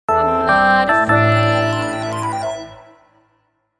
05_EKS-Ringtone-1.mp3